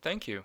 thank you positive
thankyou-pos.wav